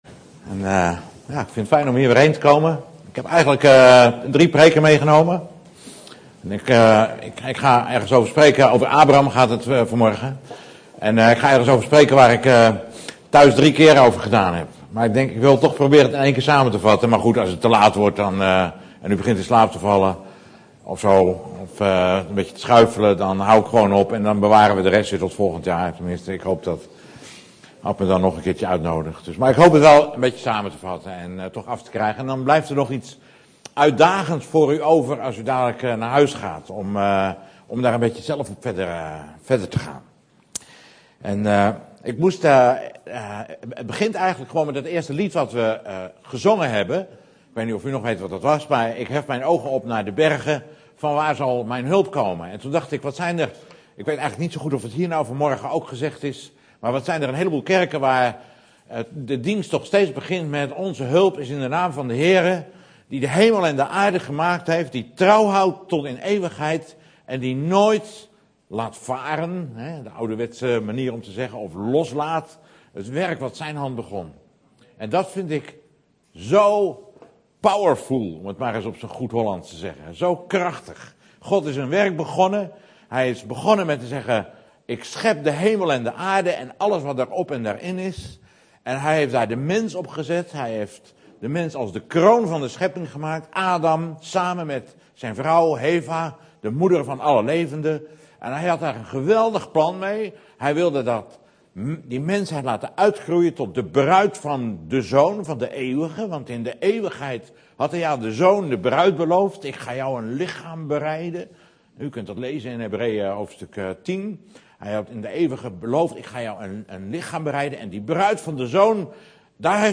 In de preek aangehaalde bijbelteksten